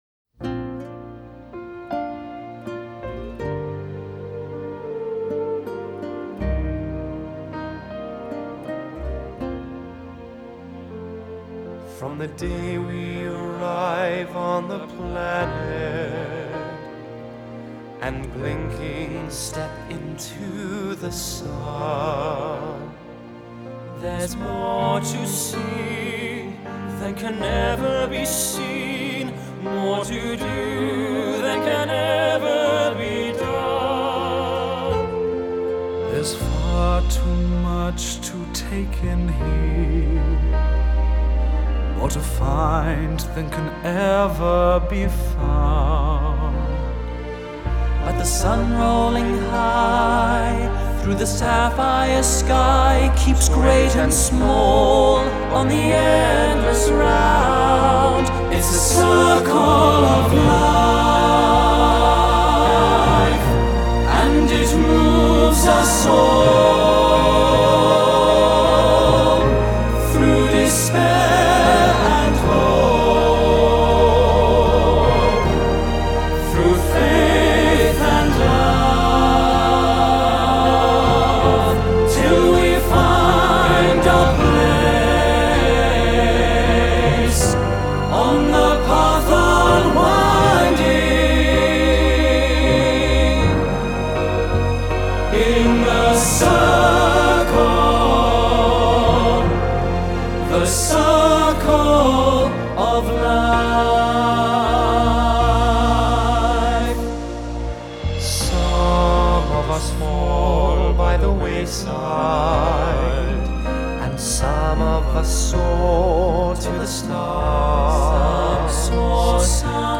Genre: Vocal, Pop, Classical